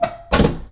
metalLightOnMetal_start.WAV